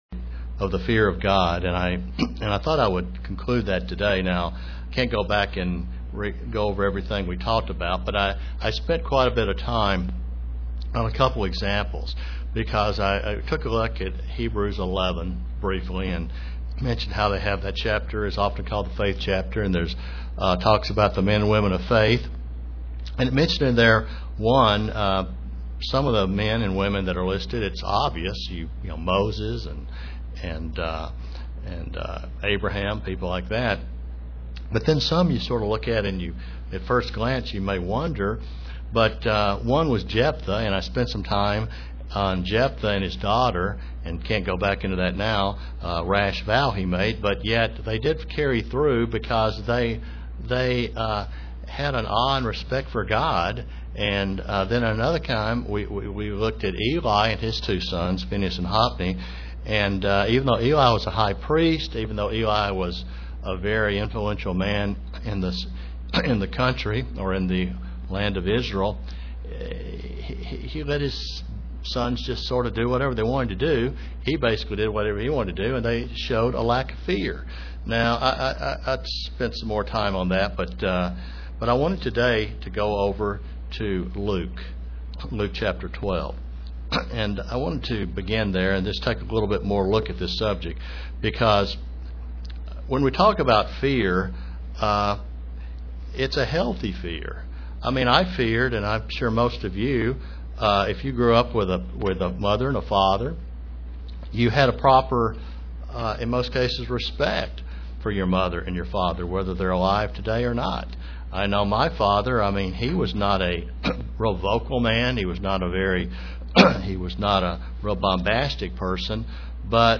Given in Kingsport, TN
UCG Sermon Studying the bible?